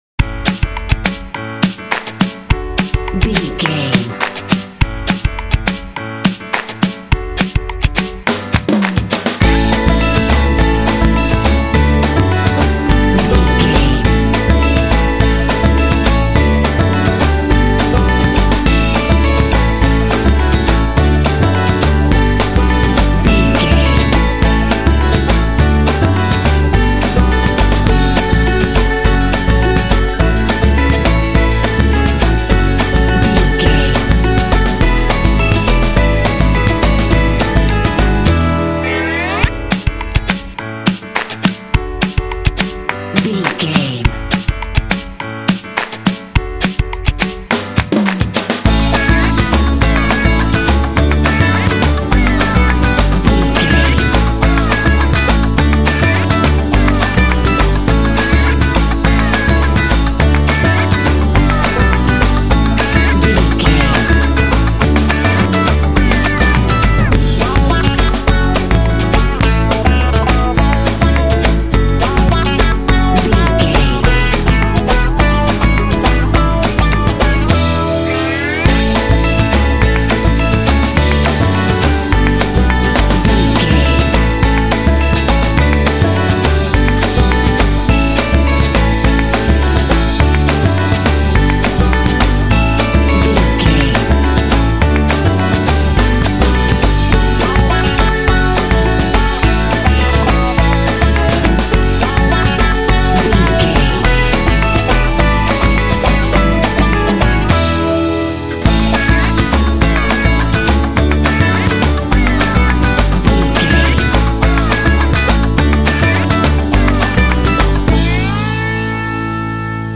This tune is super cheerful and full of energy.
Ionian/Major
Fast
driving
bouncy
groovy
bright
motivational
bass guitar
electric guitar
drums
acoustic guitar
rock
pop
alternative rock
indie